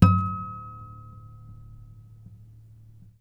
harmonic-07.wav